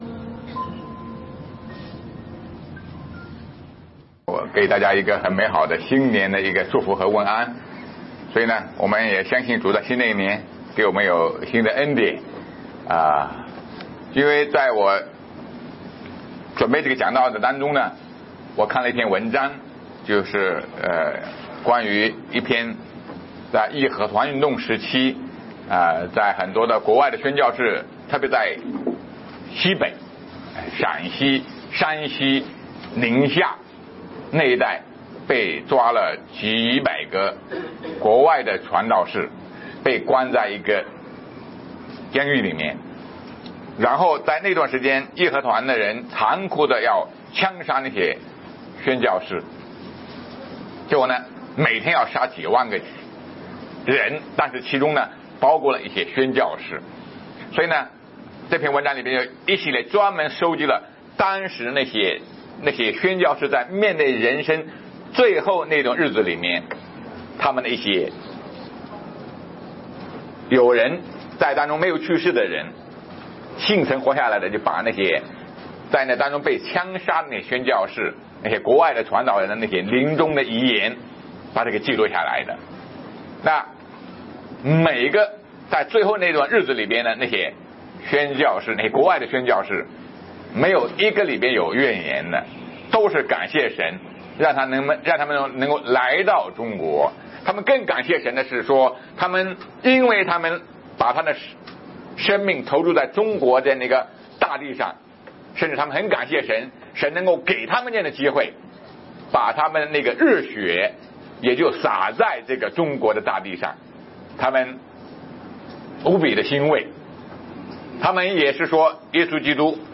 马太福音第37讲 对君王的排斥VI 2018年1月7日 上午10:55 作者：admin 分类： 马太福音圣经讲道 阅读(4.65K